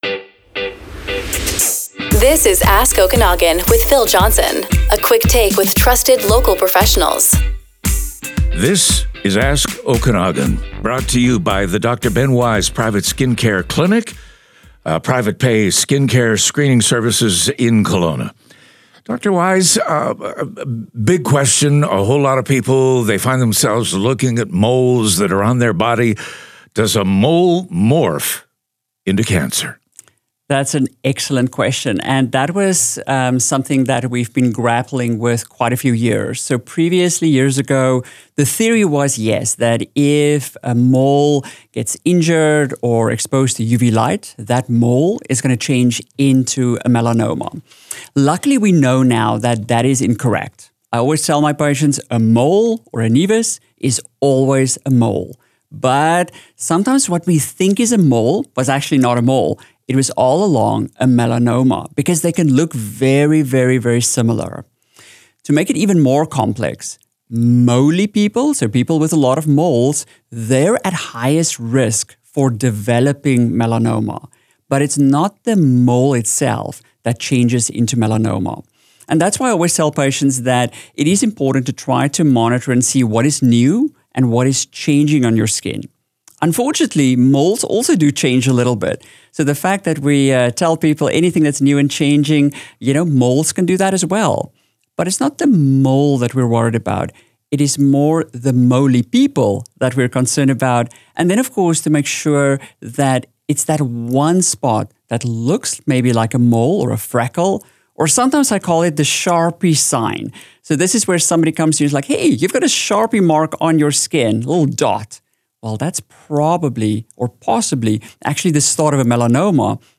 Interviews
This media recording reflects a public interview and is shared for educational purposes only.